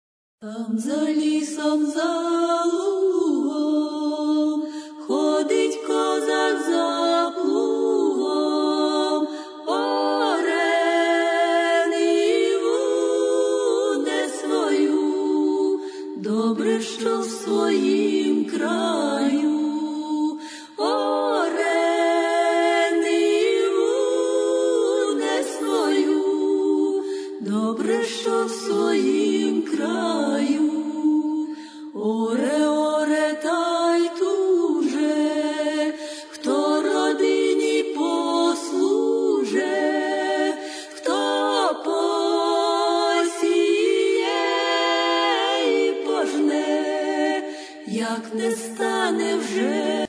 Народна (248)